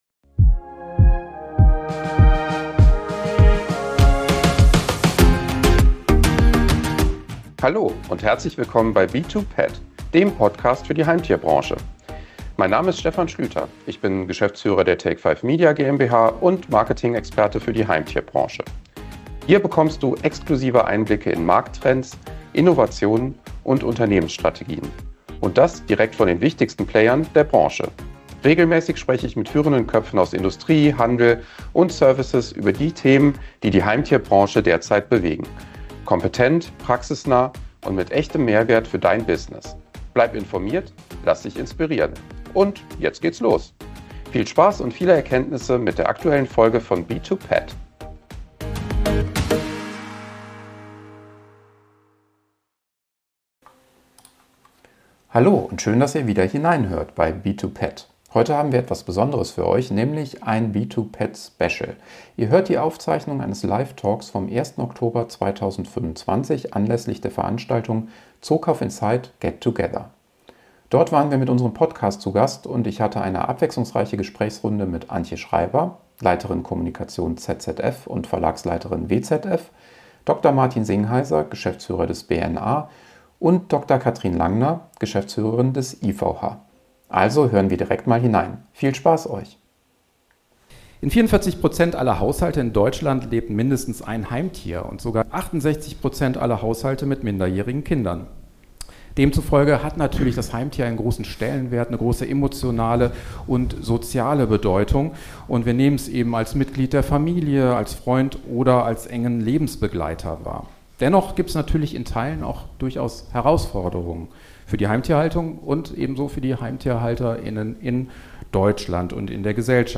Am 1. Oktober 2025 haben wir im Rahmen einer Branchenveranstaltung eine besondere Live-Ausgabe unseres B2PET-Podcasts aufgezeichnet.